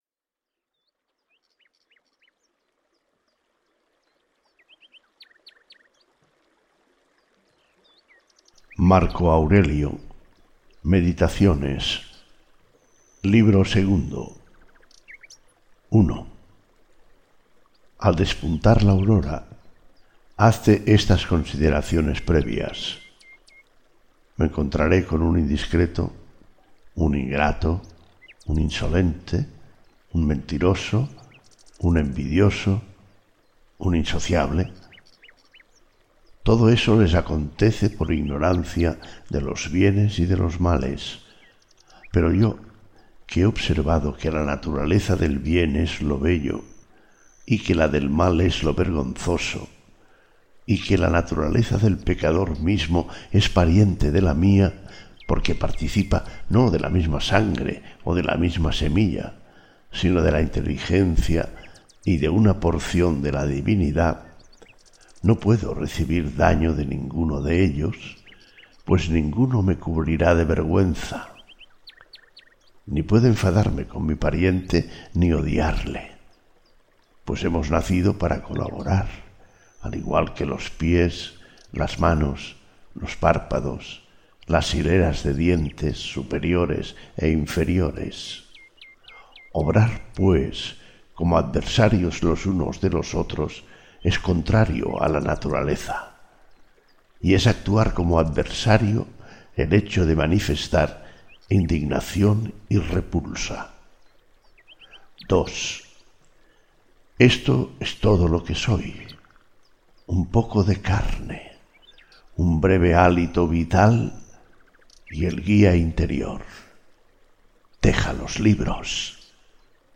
Audiolibros